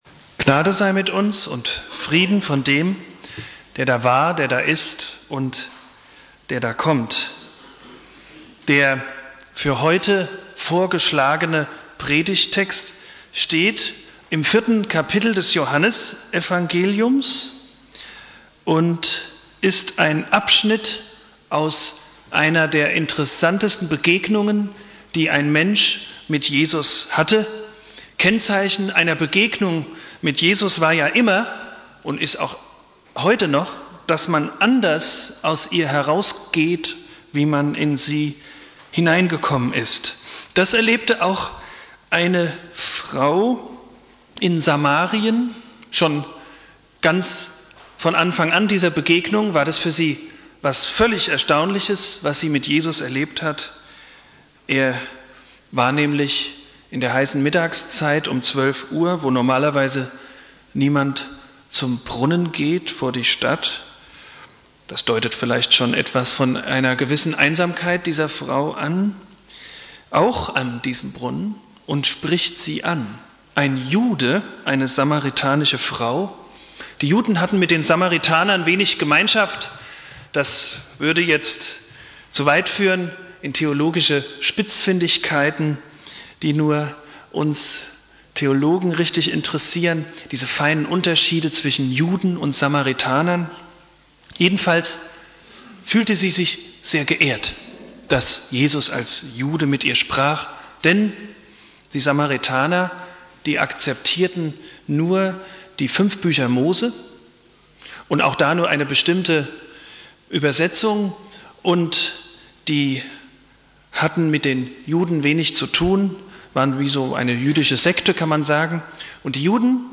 Predigt
Pfingstmontag